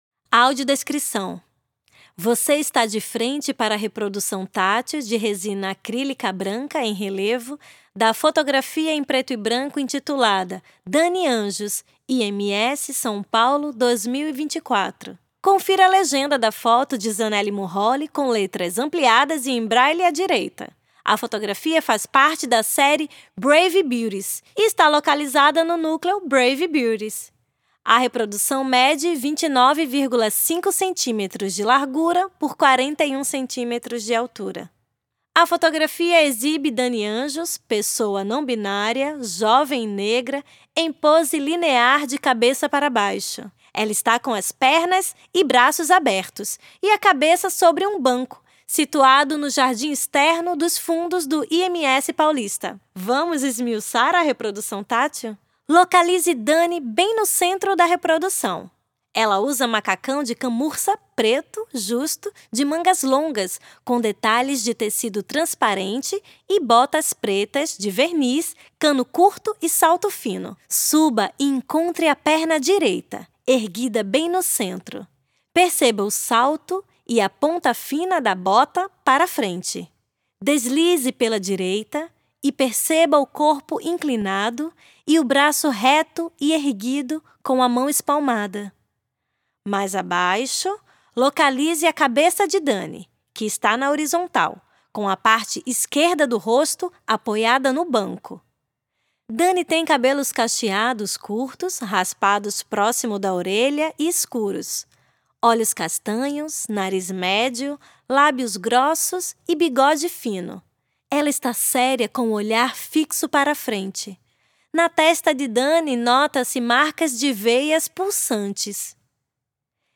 Audiodescrição - prancha tátil